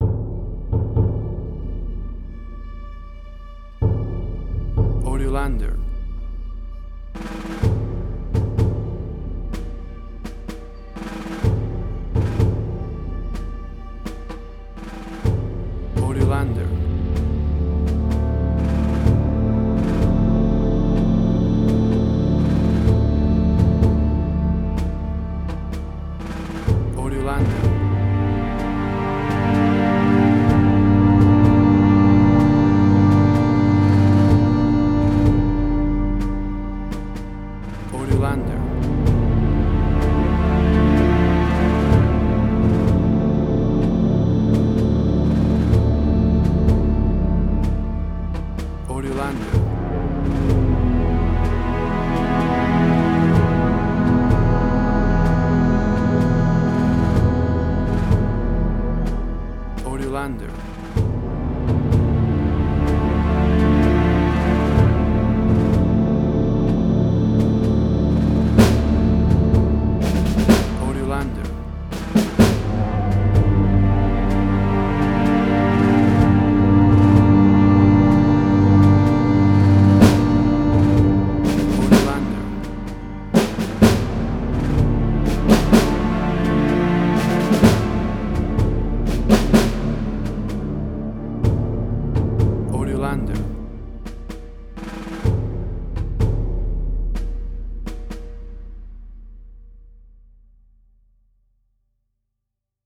Modern Film Noir.
Tempo (BPM): 63